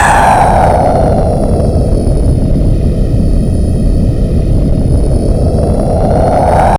Drop_FX_1_C3.wav